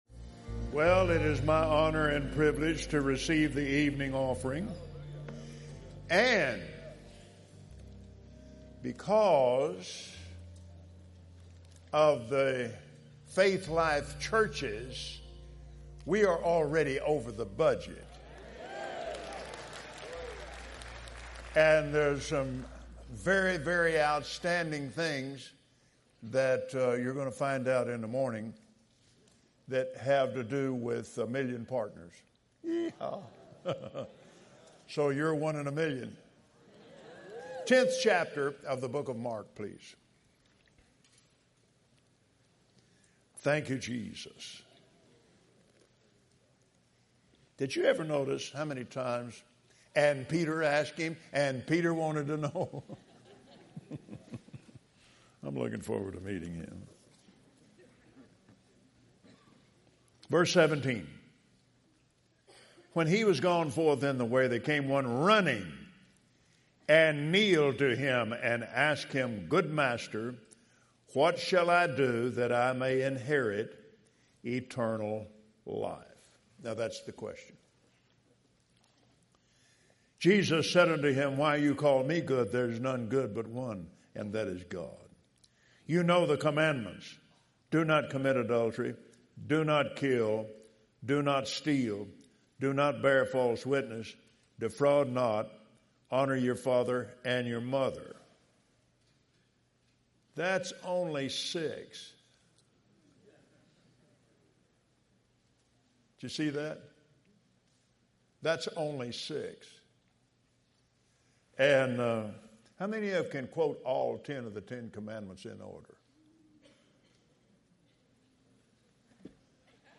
Branson Victory Campaign | Put Your Trust in God – Offering Message - Kenneth Copeland | Thurs., 7 p.m.